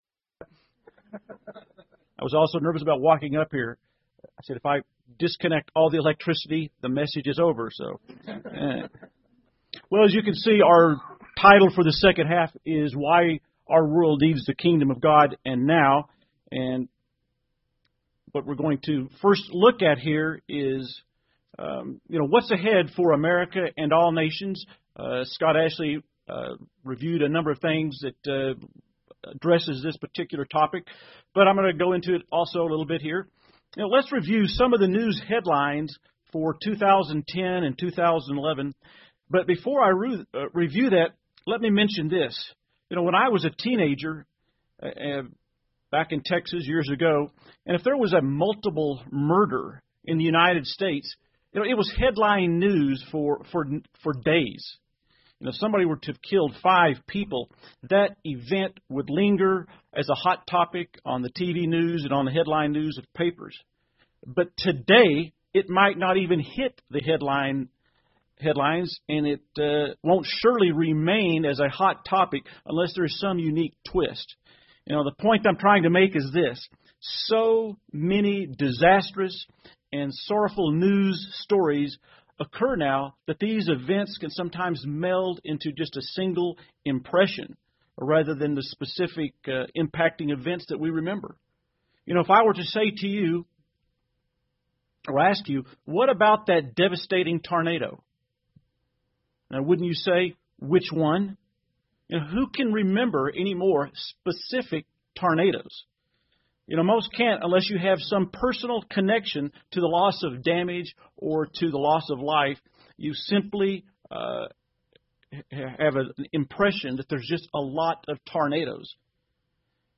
Given in Denver, CO
UCG Sermon Studying the bible?